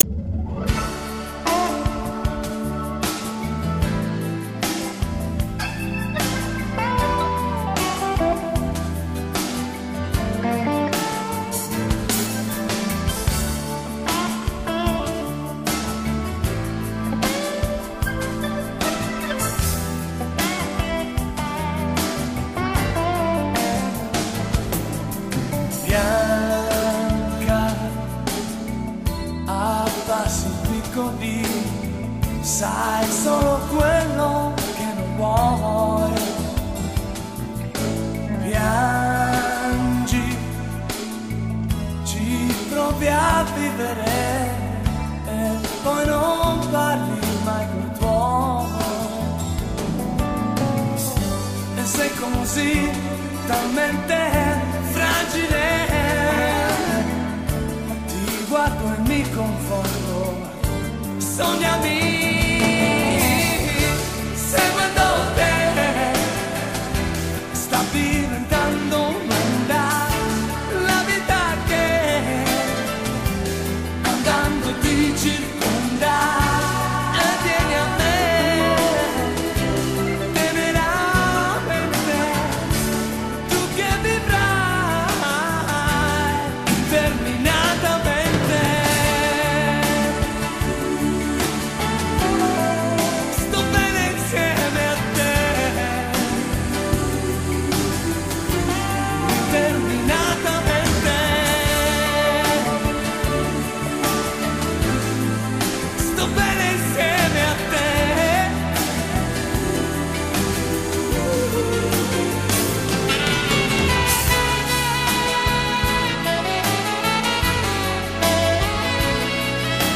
LATO 'B'=> STRUMENTALE!